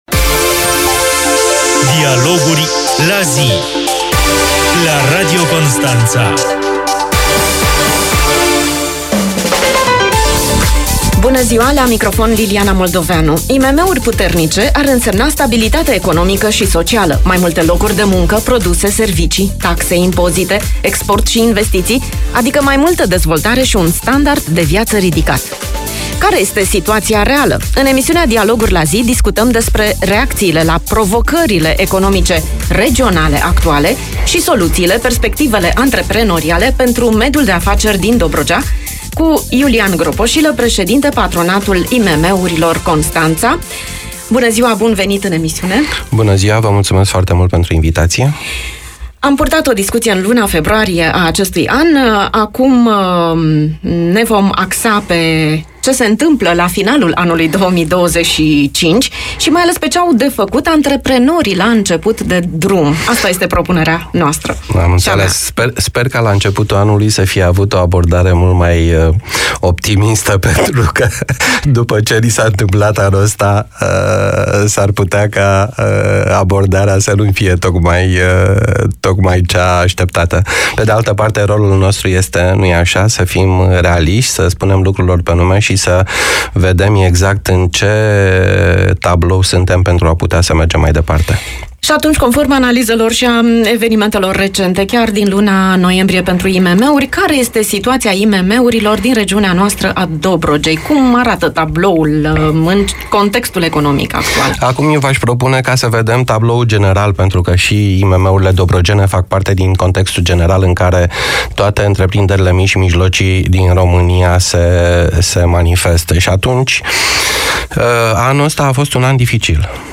invitat în emisiunea de astăzi